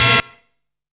POPSY HORN.wav